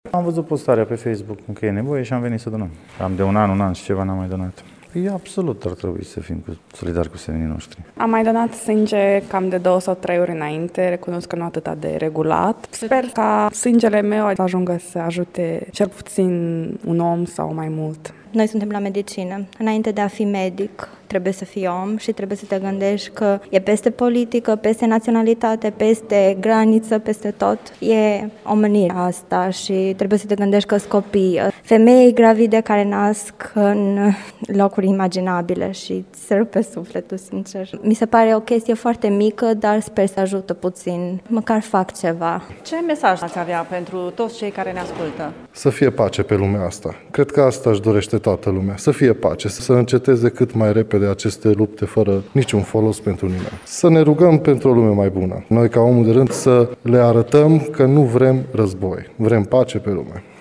Impresionați de drama din Ucraina și de criza refugiaților, târgumureșenii s-au grăbit să dea o mână de ajutor și să se roage, în același timp, pentru pace: